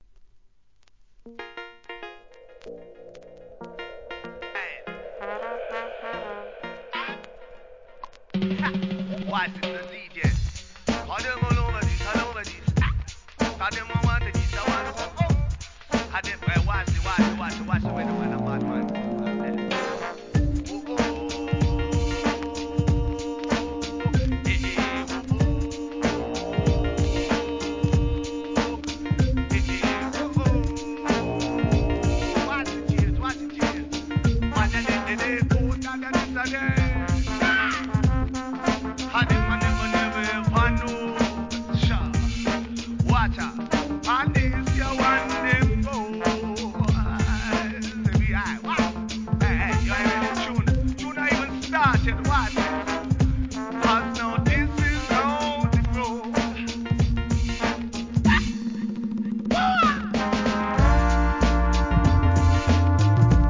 REGGAE, ラガHIP HOP.